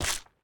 resin_break4.ogg